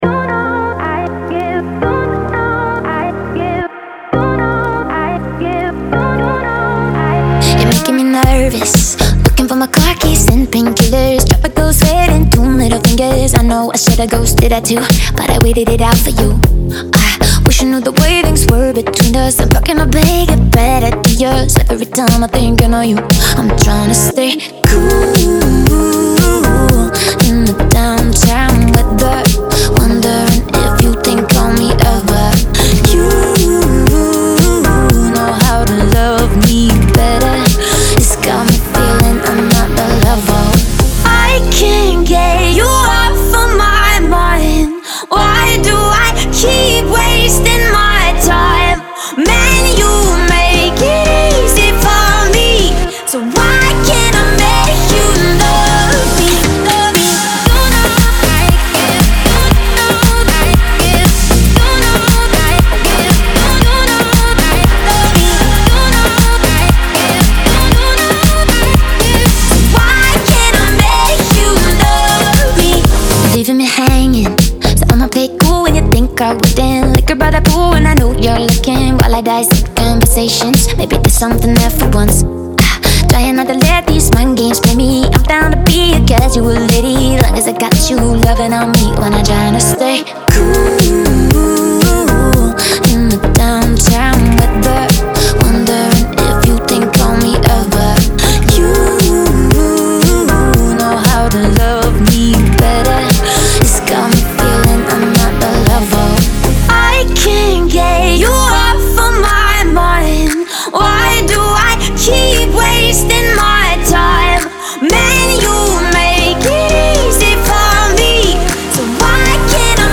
BPM117-117
Audio QualityPerfect (High Quality)
Dance Pop song for StepMania, ITGmania, Project Outfox
Full Length Song (not arcade length cut)